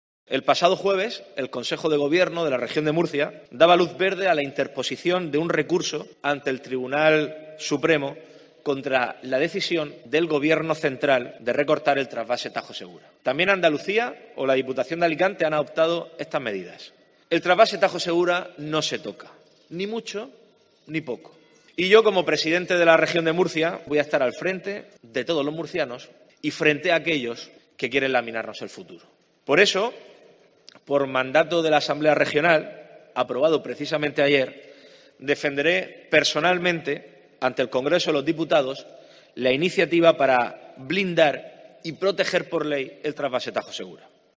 Fernando López Miras, presidente de la Región de Murcia